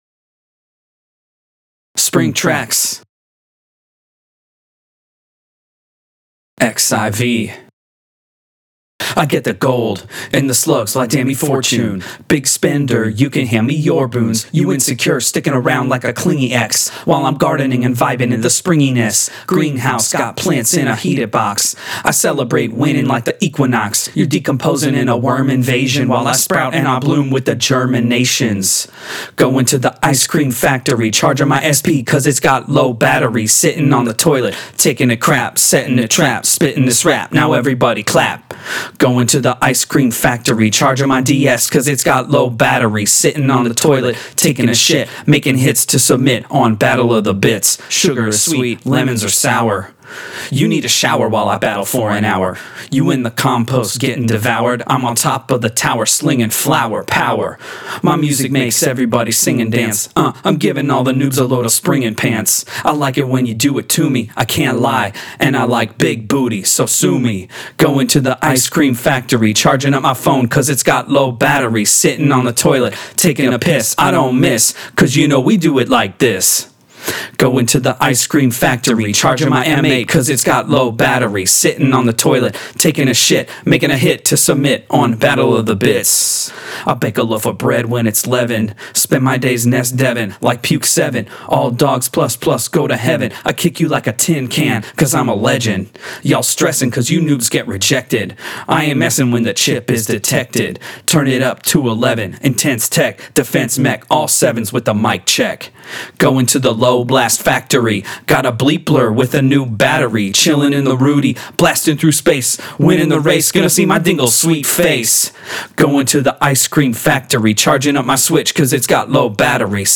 017_ICE CREAM_ CAPELLA.wav